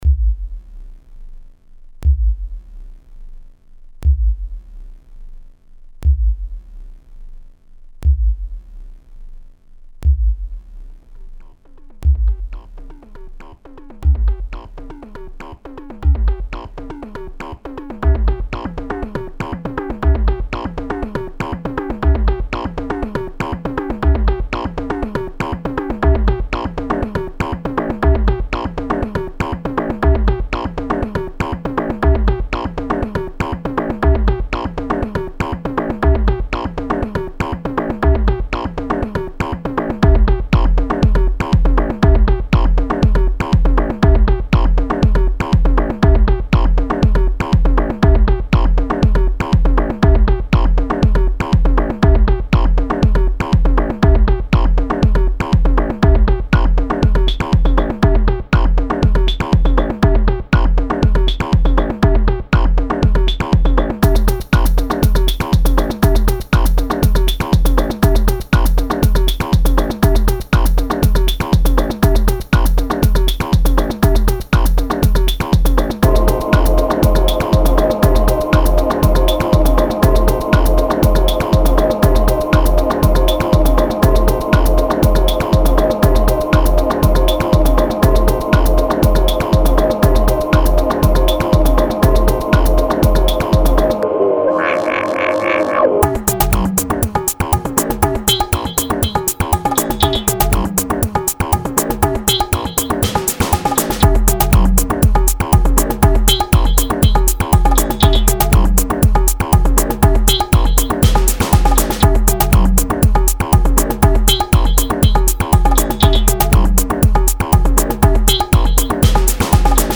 Genre Techno